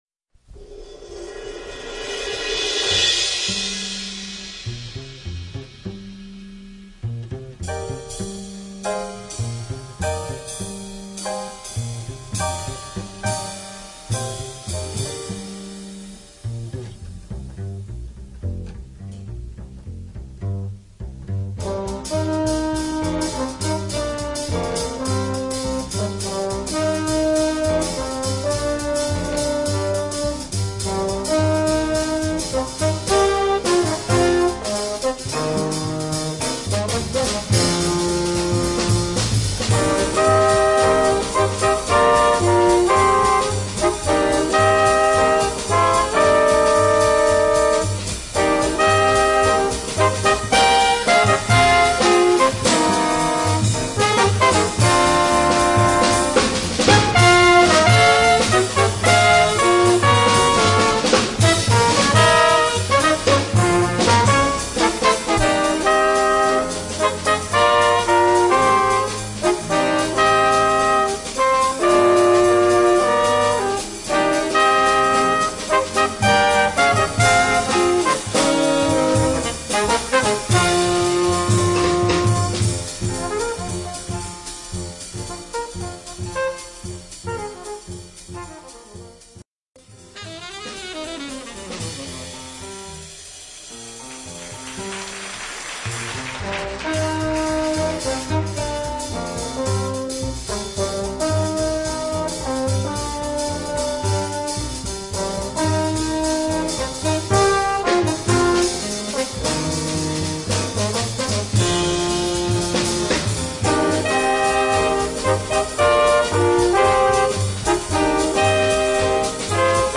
Voicing: Combo Sextet